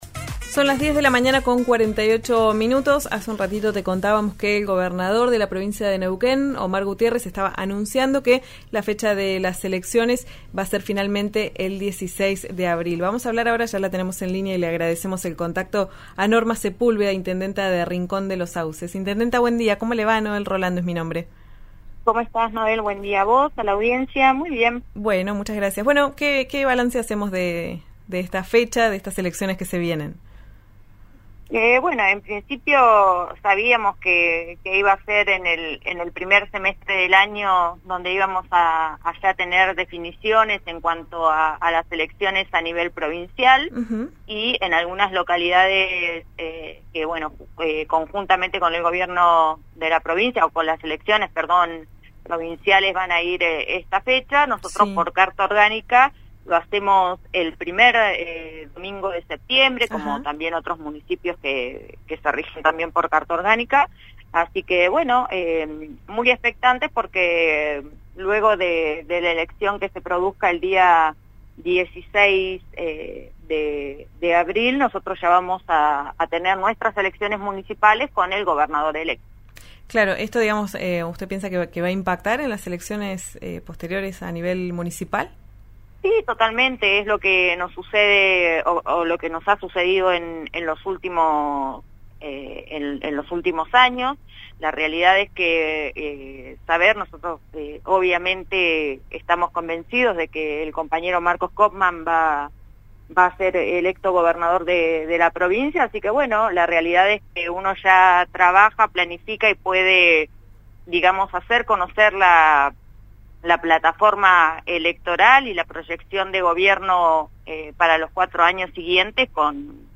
La intendenta de Rincón de los Sauces, Norma Sepúlveda, habló con “Ya es tiempo” por RÍO NEGRO RADIO. Aseguró estar “muy expectante” por la fecha de elecciones anunciada por el gobernador Omar Gutiérrez.
Escuchá a Norma Sepúlveda, intendenta de Rincón de los Sauces, en «Ya es tiempo» por RÍO NEGRO RADIO: